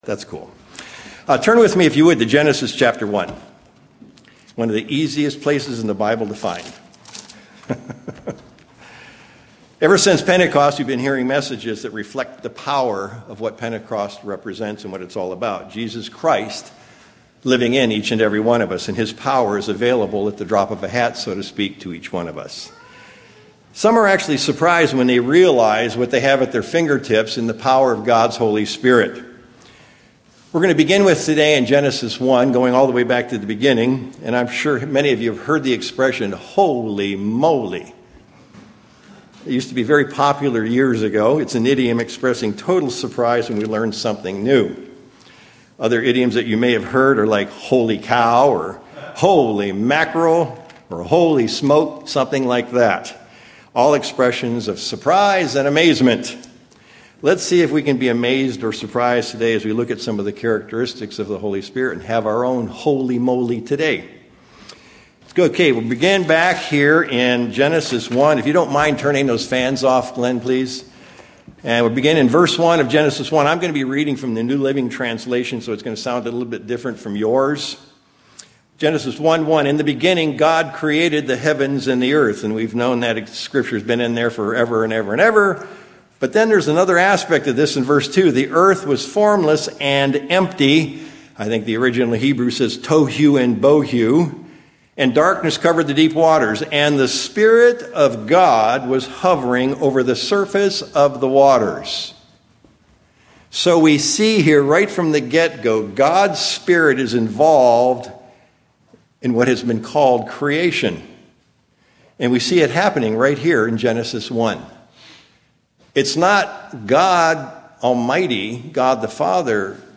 View on YouTube UCG Sermon Studying the bible?